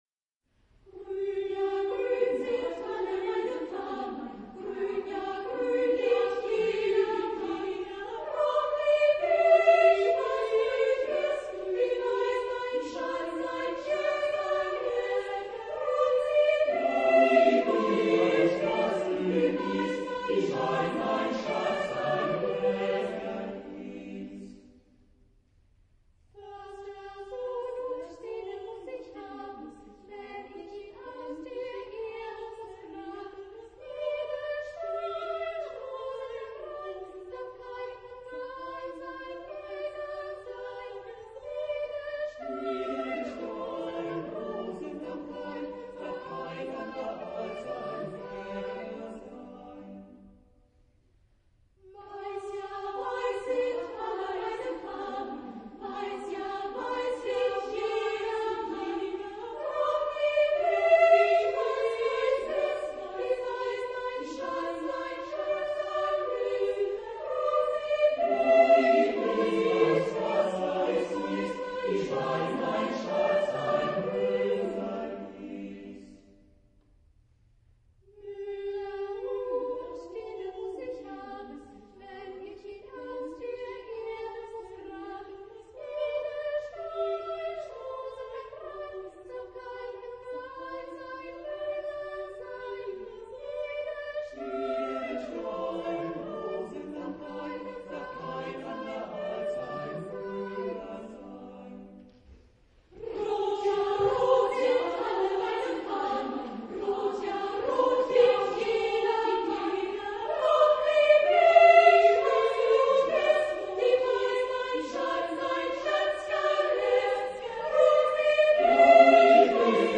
Genre-Style-Forme : Folklore ; Chanson ; Profane
Type de choeur : SATB  (4 voix mixtes )
Tonalité : sol majeur
Réf. discographique : 7. Deutscher Chorwettbewerb 2006 Kiel